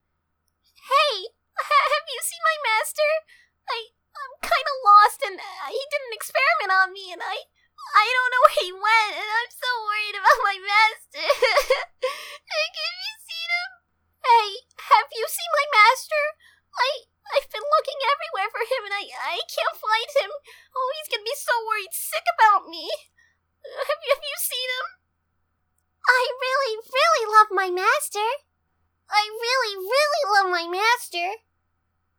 Here are some examples of the voices that original Taffy has had for inspiration.